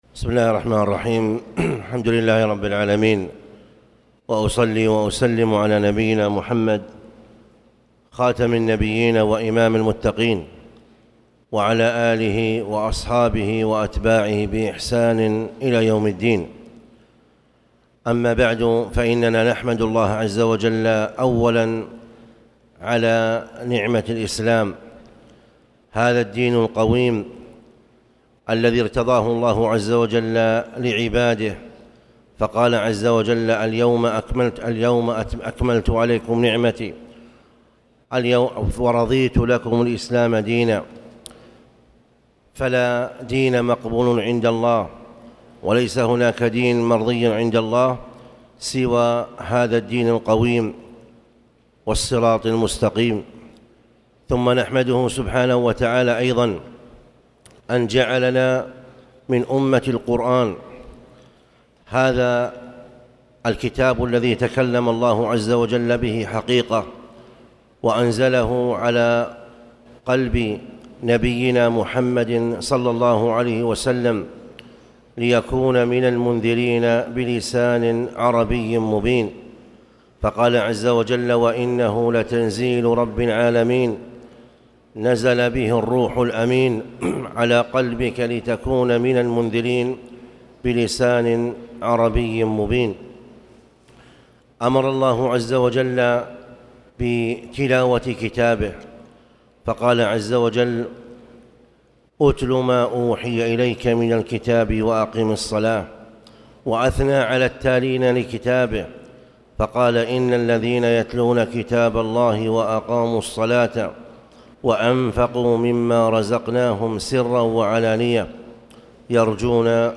تاريخ النشر ٢٥ محرم ١٤٣٨ هـ المكان: المسجد الحرام الشيخ